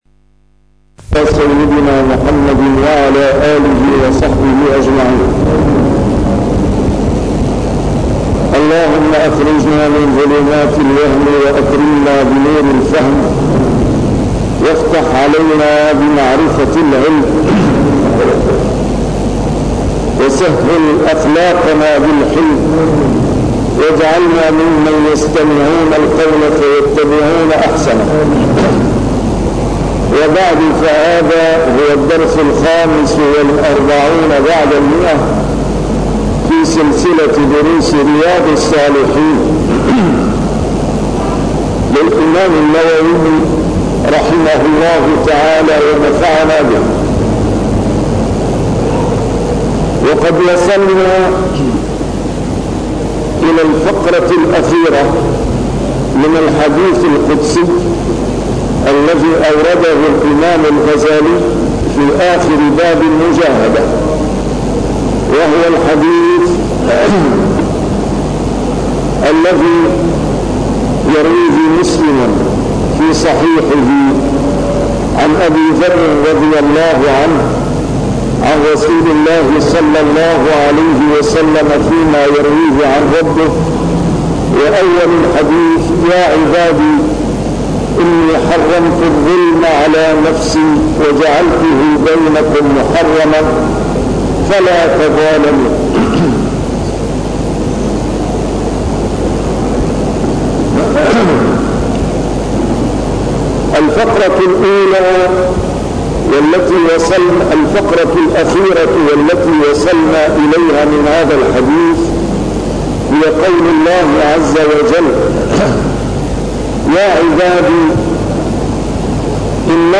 A MARTYR SCHOLAR: IMAM MUHAMMAD SAEED RAMADAN AL-BOUTI - الدروس العلمية - شرح كتاب رياض الصالحين - 145- شرح رياض الصالحين: المجاهدة